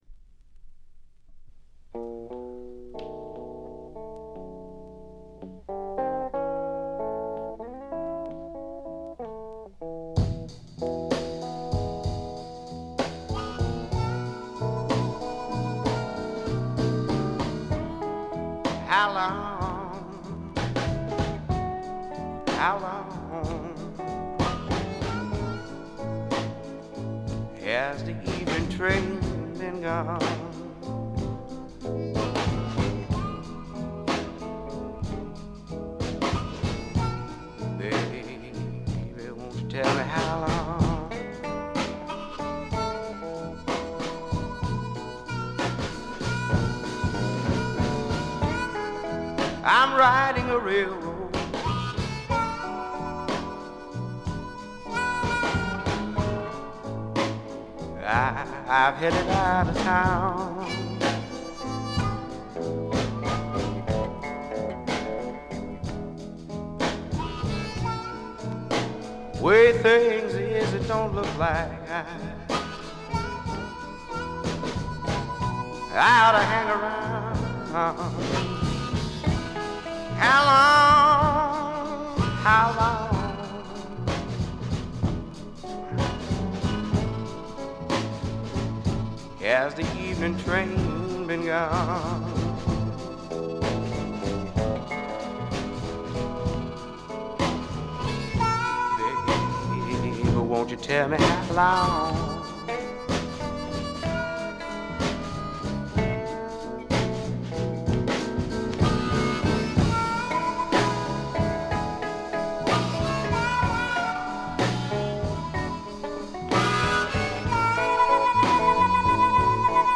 デモと言ってもブルース、R&B色が色濃い素晴らしい演奏を聴かせます。
試聴曲は現品からの取り込み音源です。